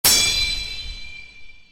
sword-clash.wav